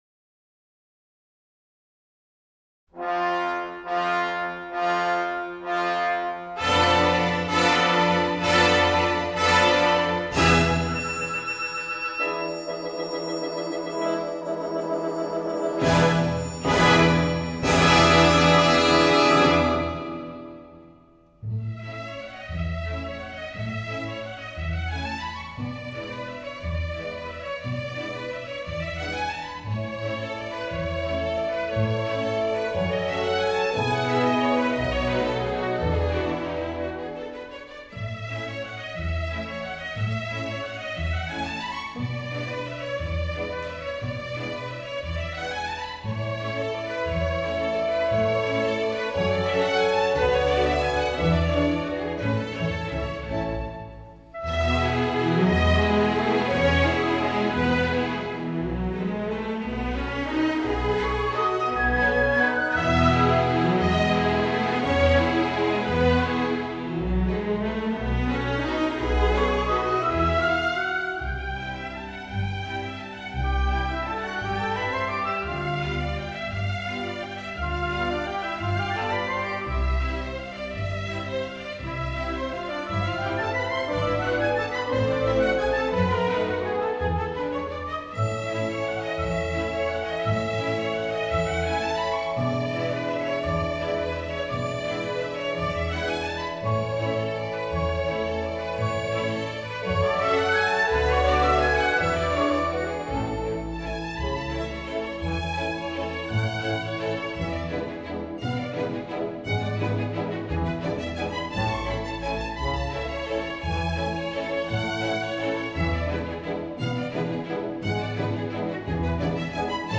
Главная / Песни для детей / Классическая музыка